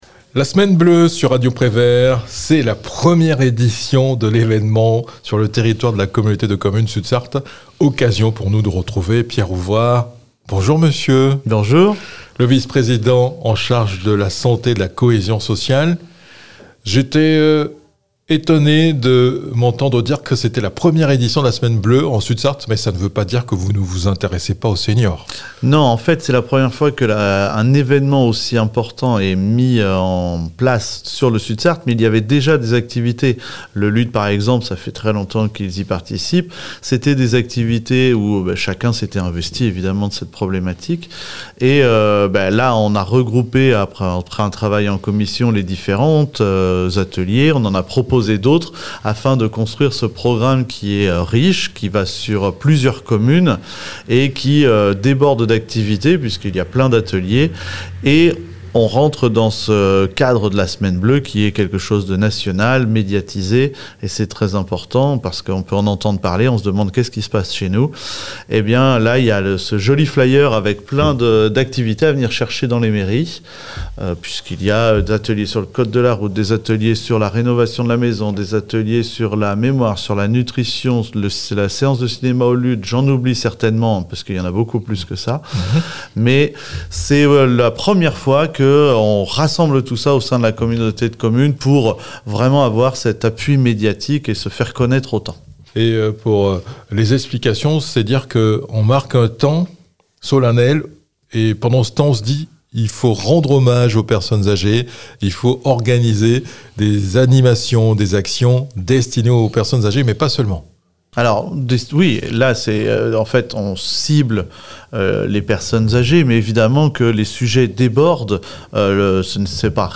Pourquoi l'inter CCAS et le centre social l'EQUIP'Ages ont fait cause commune pour l'occasion? La réponse avec Pierre Ouvrard, vice-président en charge de la santé et du social.